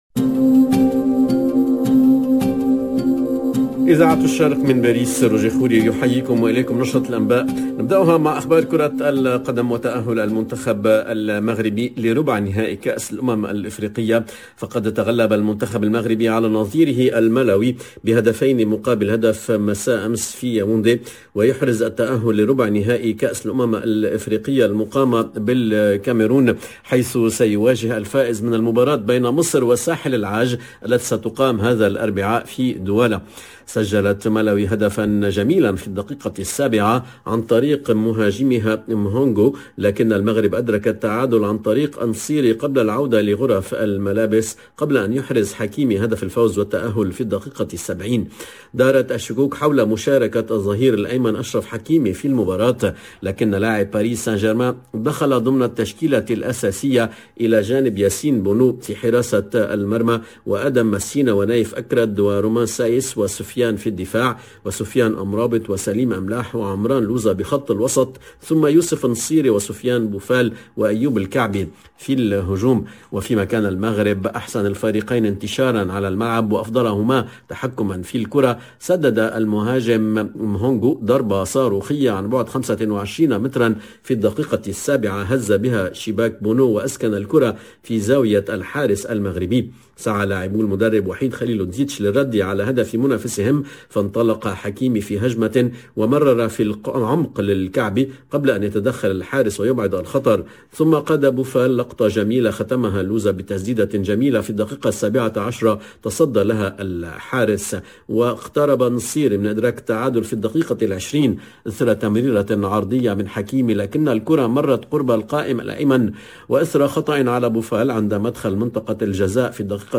LE JOURNAL DE MIDI 30 EN LANGUE ARABE DU 26/01/22